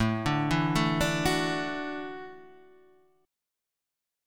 A6sus4 chord {5 5 2 2 3 2} chord